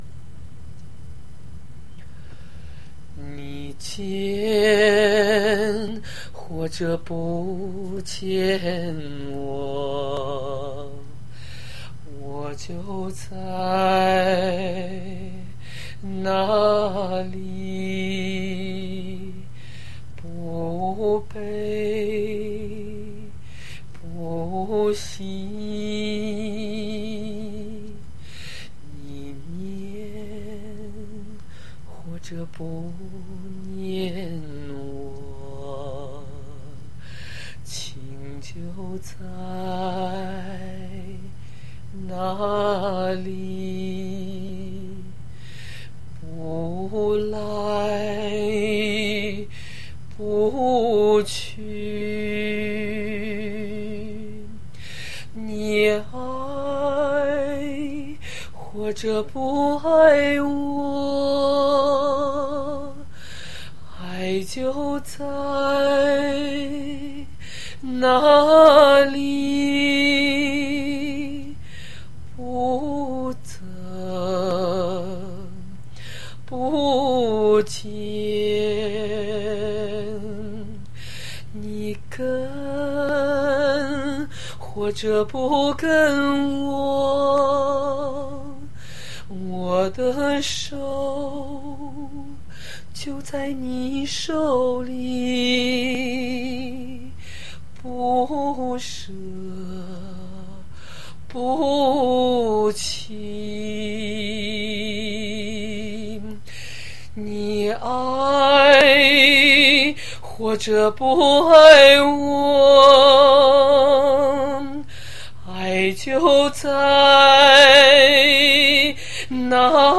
初学西藏风格，神往久矣。
一个清唱。或许无伴奏影响不算太大。
应该是一首流行女声。